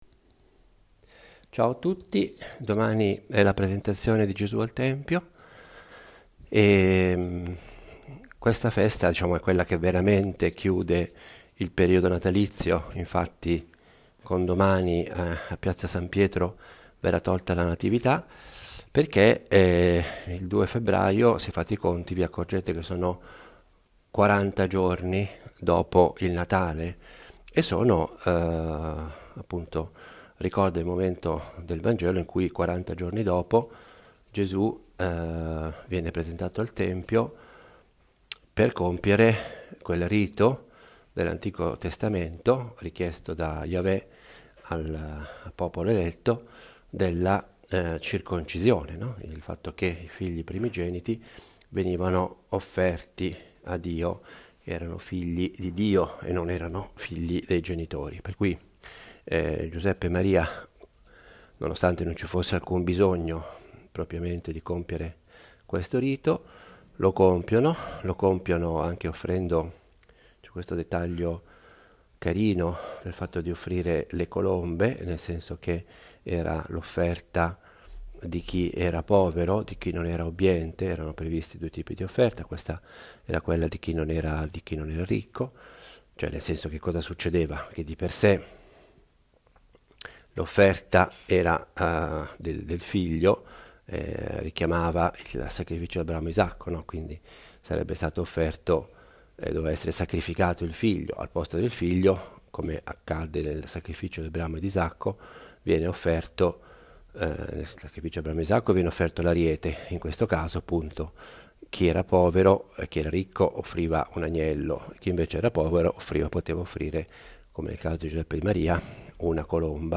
Omelia della Presentazione del Signore
Vorrei avesse il carattere piano, proprio di una conversazione familiare.